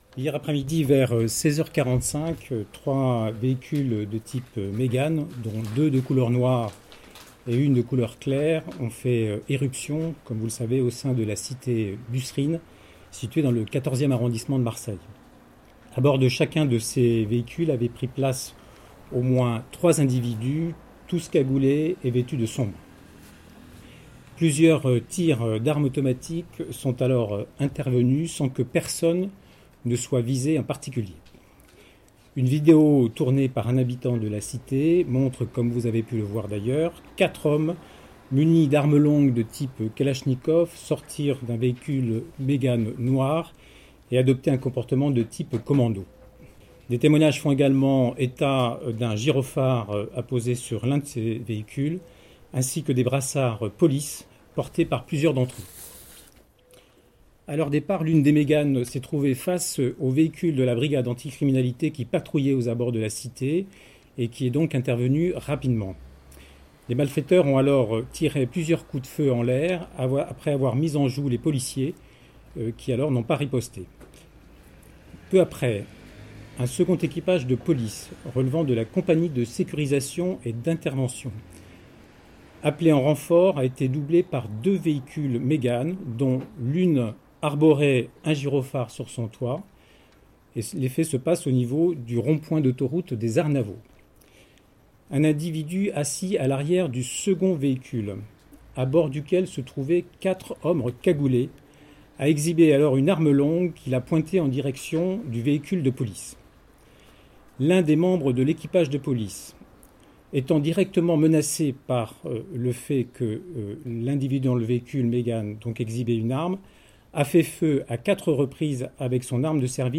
son_copie_petit-236.jpg L’enquête se poursuit ce mardi après la fusillade qui a éclaté lundi après-midi dans la cité de la Busserine à Marseille (14e). Cette fusillade qui n’a pas fait de blessé par balles serait «une action d’intimidation» liée au trafic de stupéfiants commis au sein de cette cité marseillaise et relèverait du «narco-banditisme, a souligné Xavier Tarabeux, procureur de la République de Marseille, lors d’une conférence de presse ce mardi après-midi.
procureur_xavier_tarabeux_sur_busserine_22_5_2018.mp3